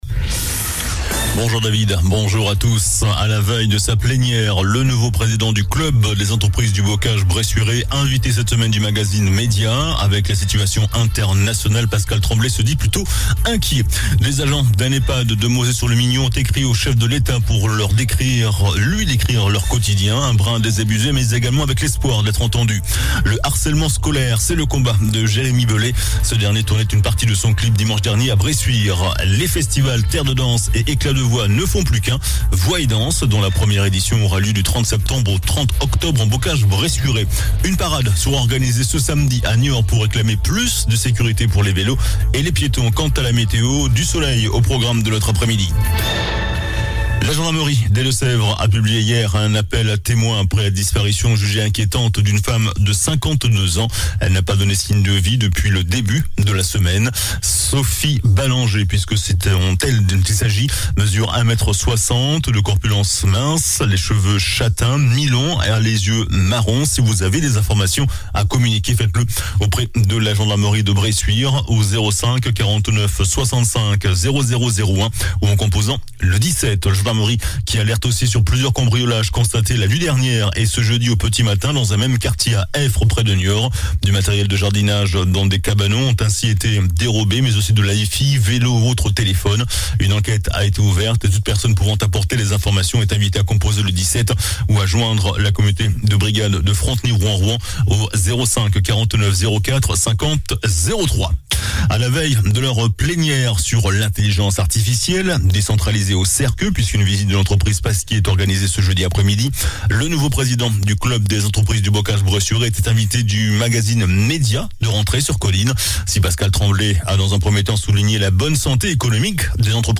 JOURNAL DU JEUDI 22 SEPTEMBRE ( MIDI )